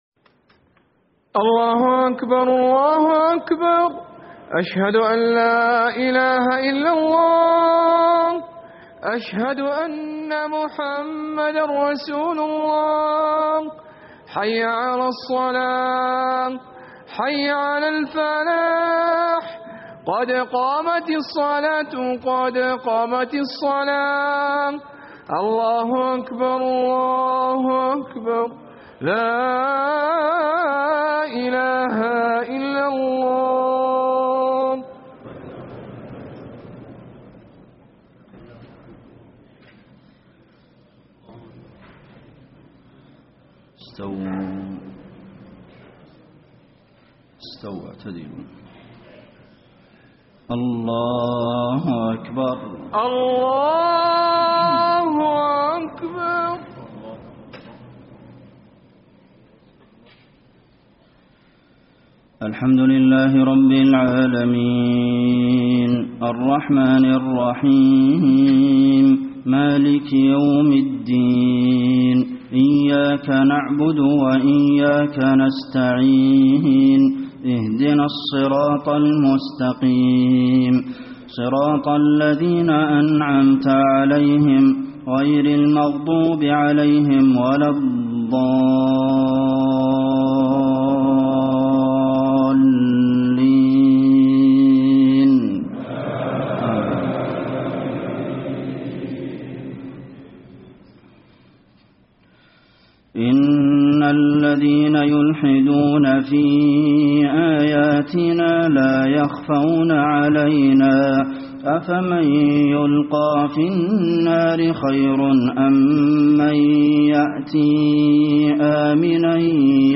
صلاة الجمعة 9-6-1434 من سورة فصلت > 1434 🕌 > الفروض - تلاوات الحرمين